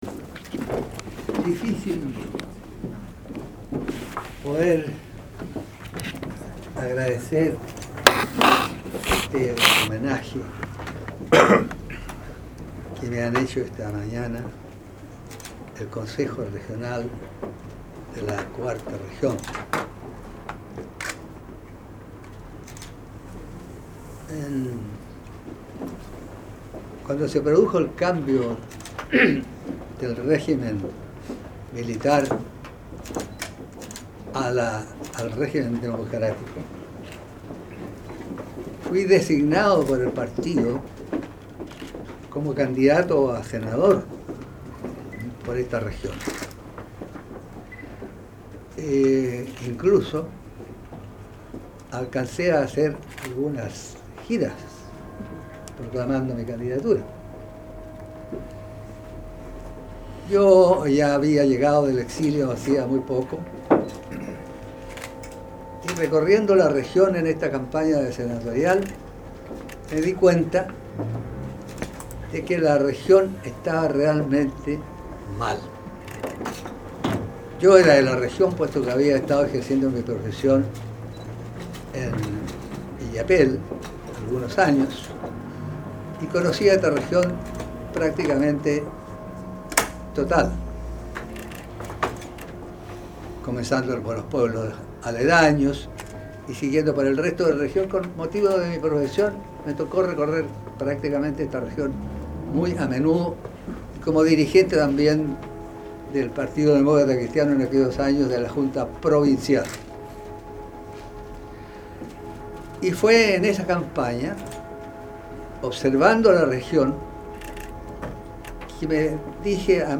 Emotivo reconocimiento del CORE a ex intendente Renán Fuentealba
El distinguido servidor público reveló en su intervención, tras recibir el reconocimiento del CORE, que su llegada a la intendencia fue por disposición propia tras renunciar como candidato a senador por la región.
palabras_de_don_renan_fuentealba.mp3